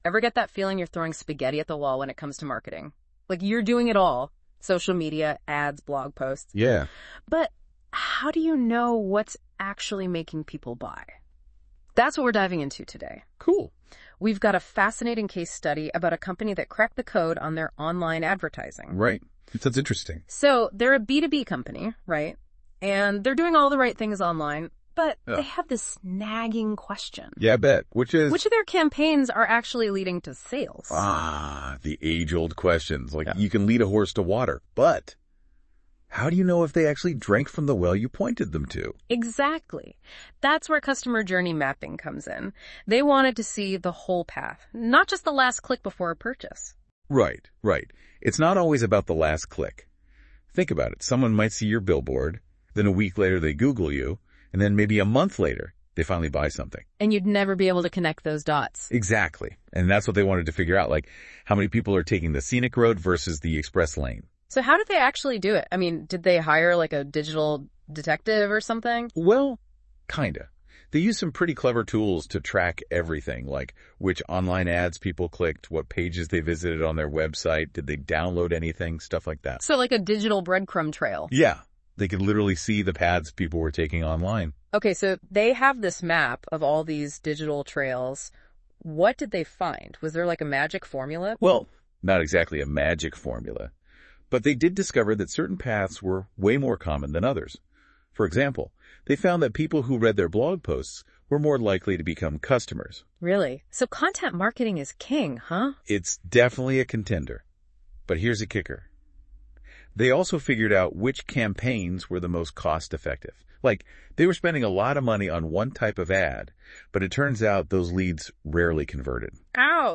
Note : This Podcast is generated through Notebook LM. Curated and Verified by Aryma Labs for Accuracy.